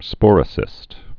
(spôrə-sĭst)